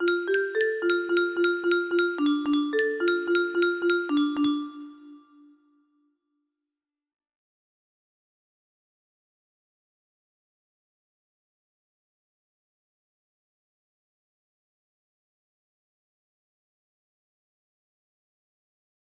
Grand Illusions 30 (F scale)